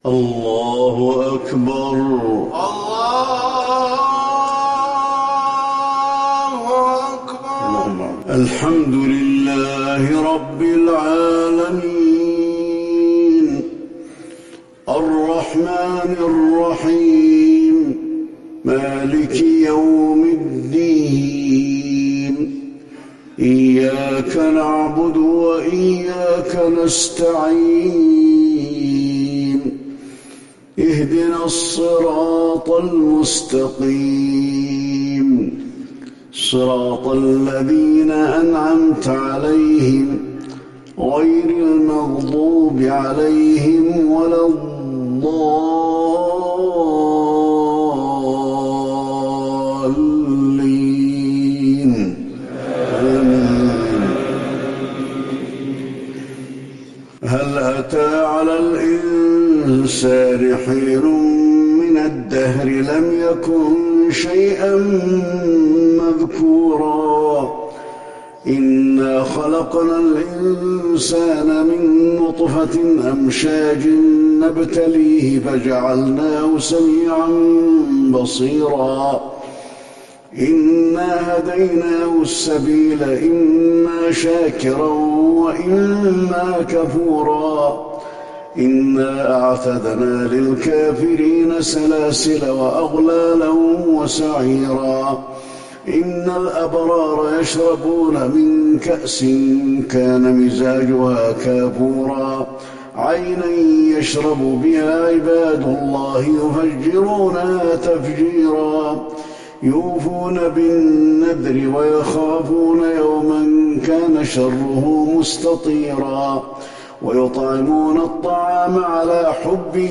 صلاة الفجر للشيخ علي الحذيفي 6 جمادي الآخر 1441 هـ
تِلَاوَات الْحَرَمَيْن .